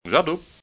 Wir bieten hier zwei mp3-Klingeltöne an, mit denen man gute Chancen hat, der Strafe zu entgehen.
Klingelton 2: J'adoube...!